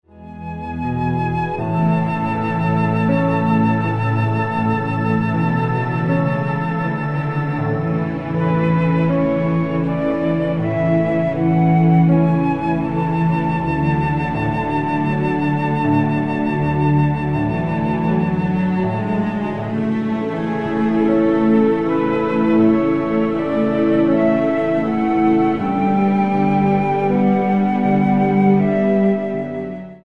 60 BPM